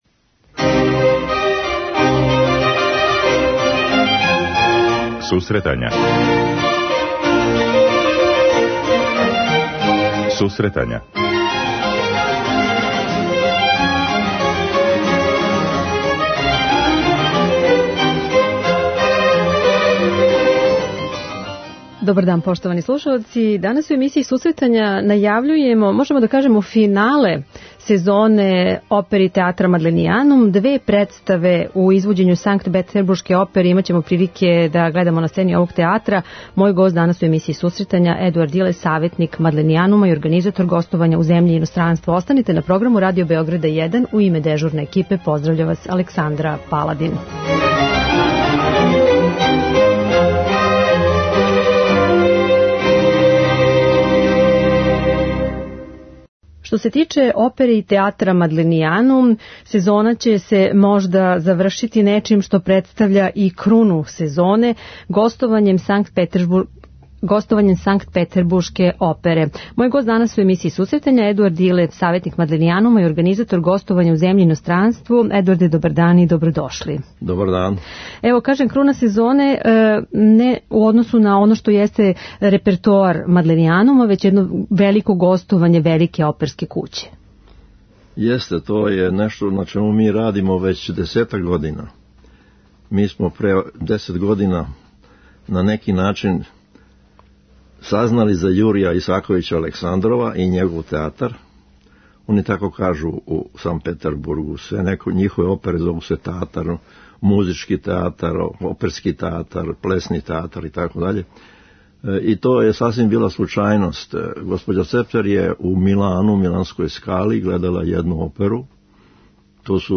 преузми : 10.68 MB Сусретања Autor: Музичка редакција Емисија за оне који воле уметничку музику.